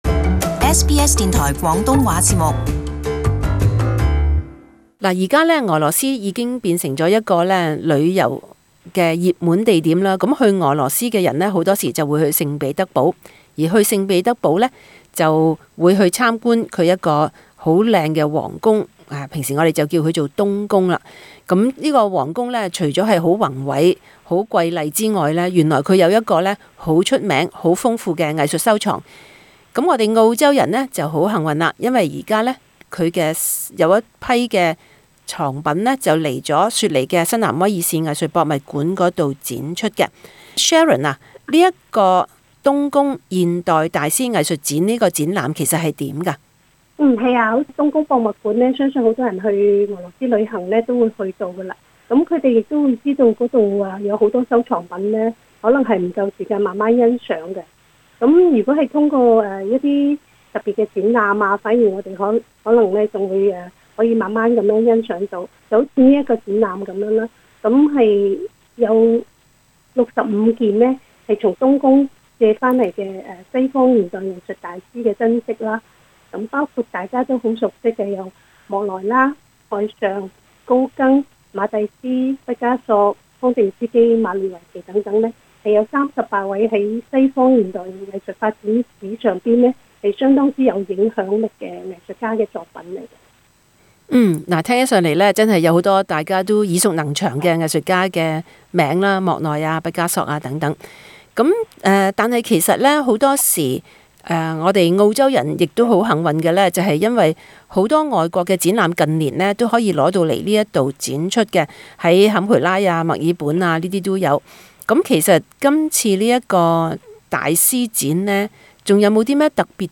【雪梨社区专访】俄罗斯冬宫现代艺术大师精品展